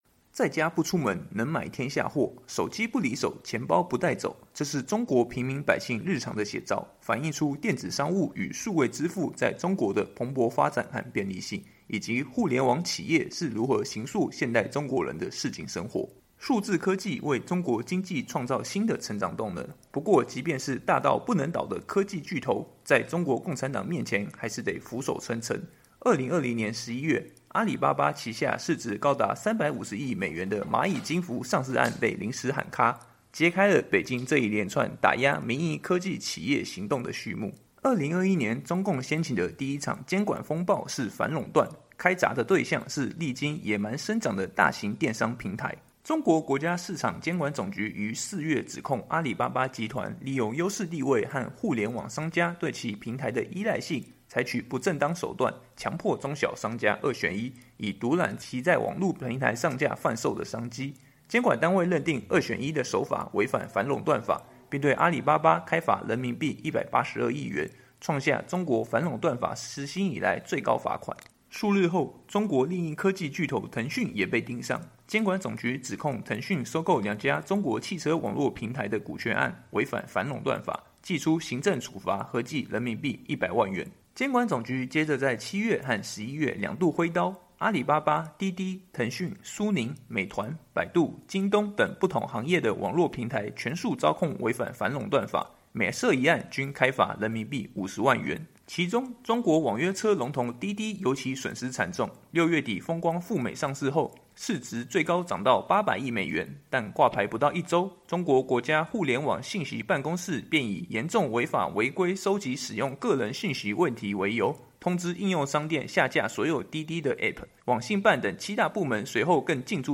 年终报道